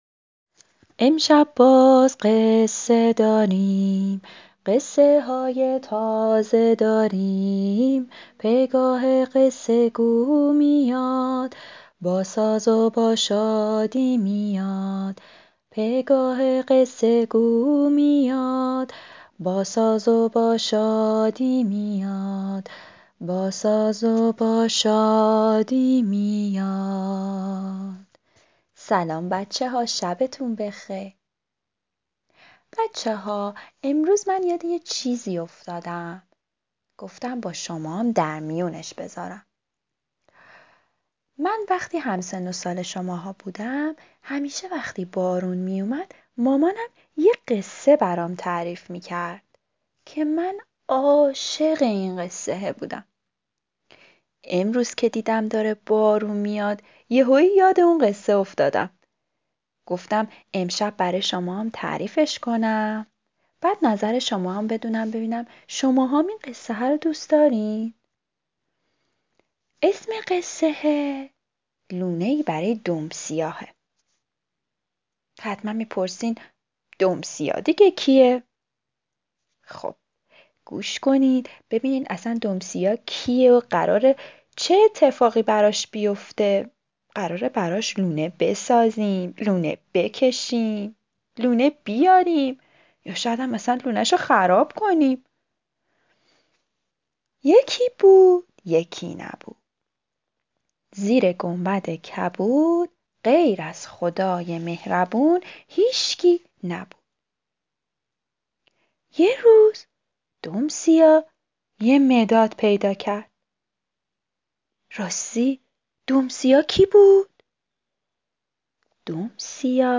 قصه صوتی کودکان 12 دیدگاه 45,094 بازدید